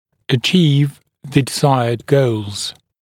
[ə’ʧiːv ðə dɪ’zaɪəd gəulz][э’чи:в зэ ди’зайэд гоулз]достичь желаемых целей